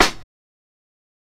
TC3Snare16.wav